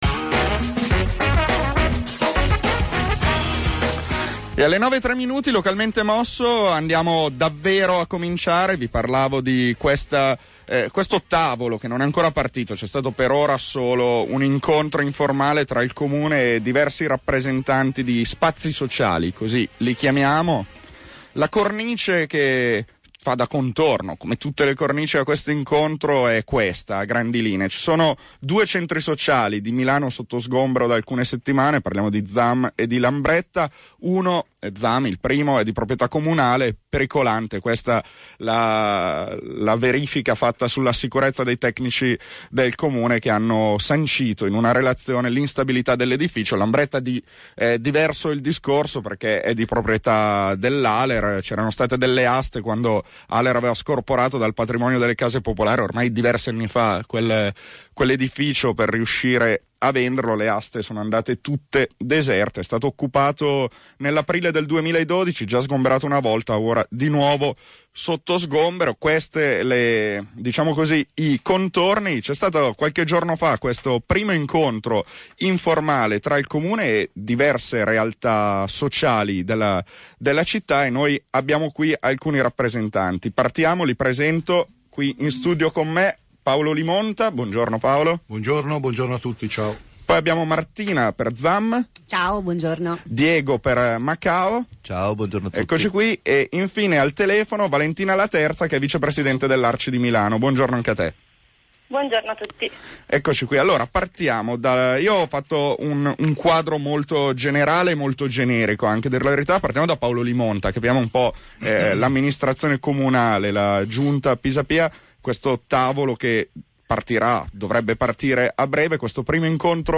S’è svolta questa mattina alle 09.00 presso gli studi di Radio Popolare una trasmissione di confronto e approfondimento sul tema degli spazi sociali.